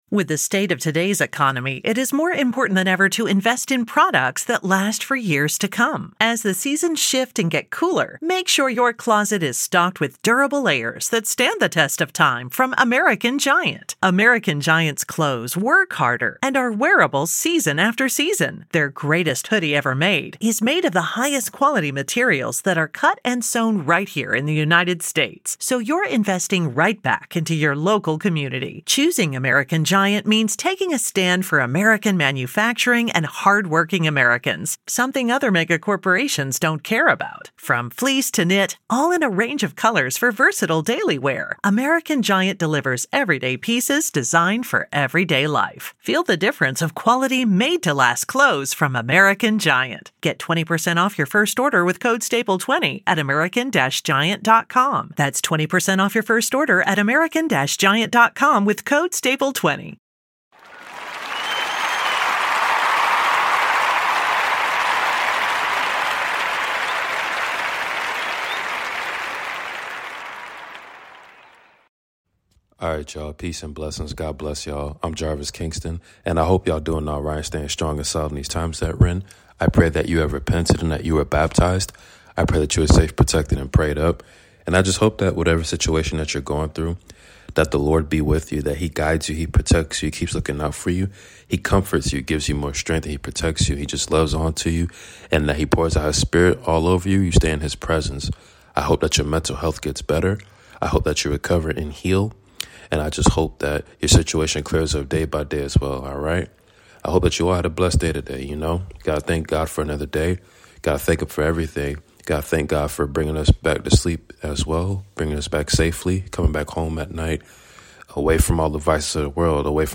Book of Exodus reading !